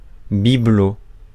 Prononciation
Prononciation France: IPA: /bi.blo/ Le mot recherché trouvé avec ces langues de source: français Traduction 1.